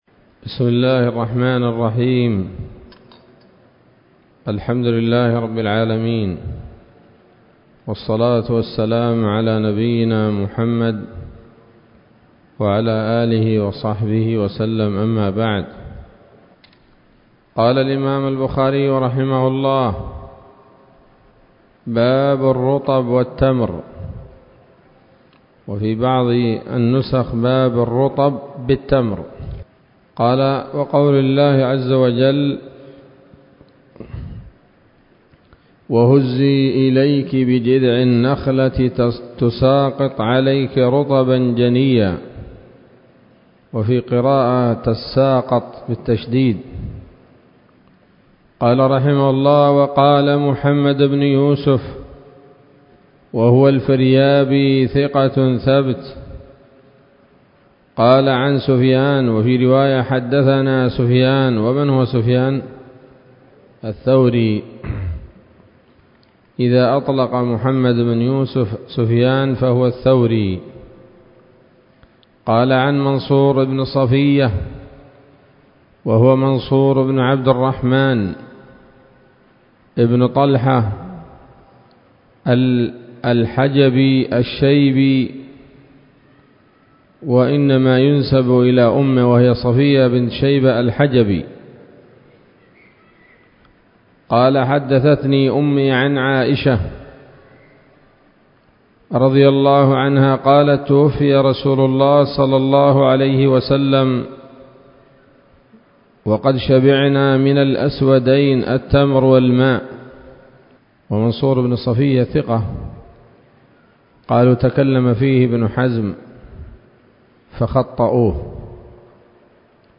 الدرس الثالث والعشرون من كتاب الأطعمة من صحيح الإمام البخاري